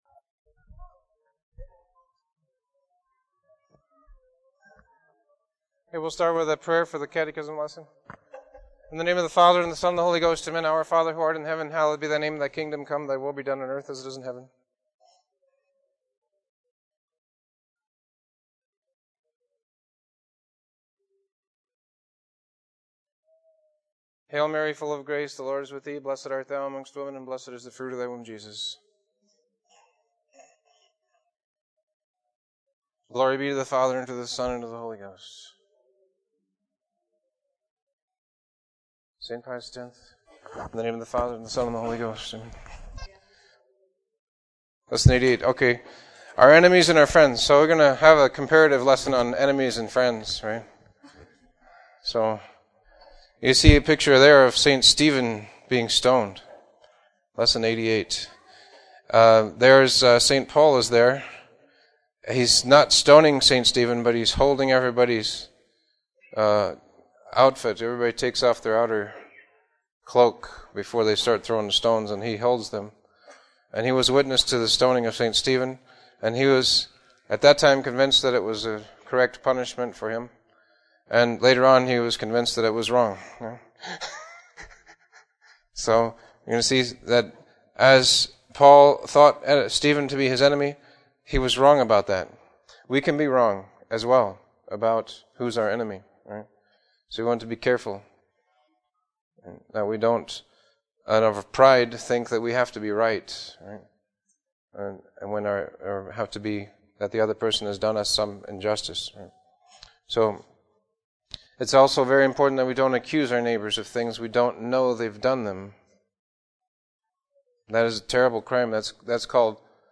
Catechism Lessons